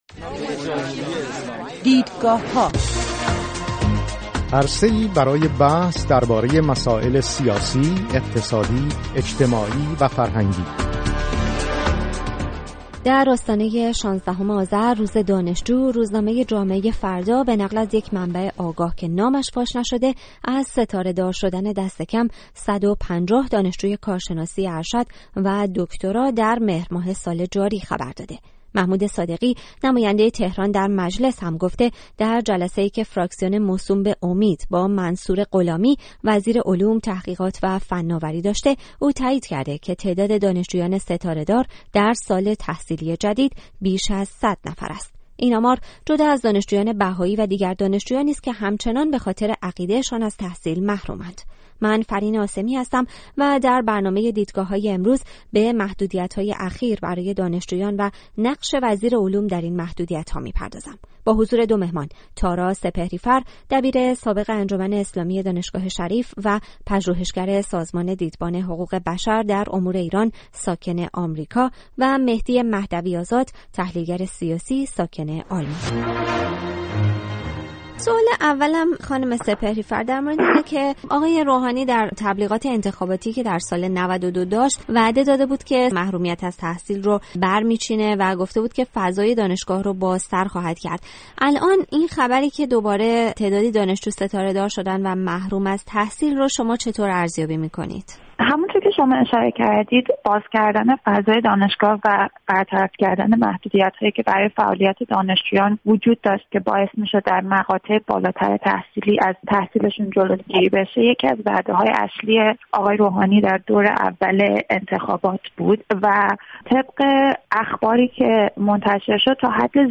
در برنامه دیدگاه‌های این هفته به محدودیت‌های اخیر برای دانشجویان و نقش وزیر علوم در این محدودیت‌ها می‌پردازیم. با حضور دو مهمان